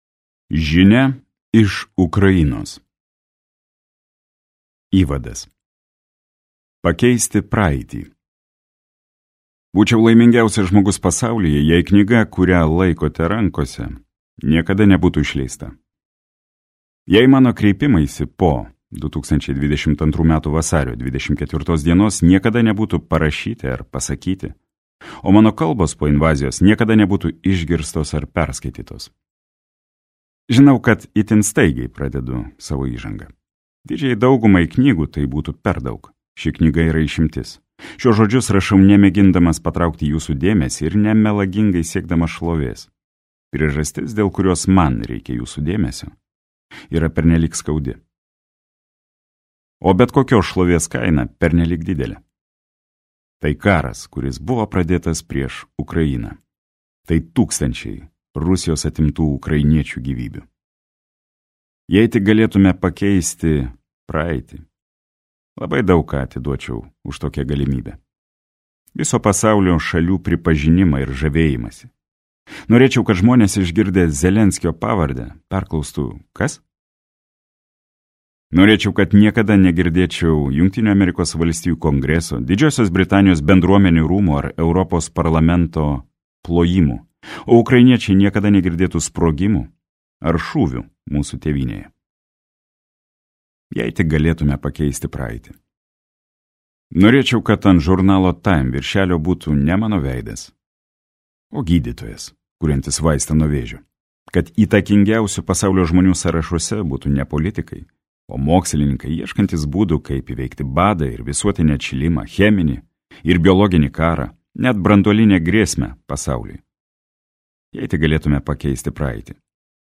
Žinia iš Ukrainos | Audioknygos | baltos lankos